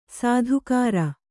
♪ sādhukāra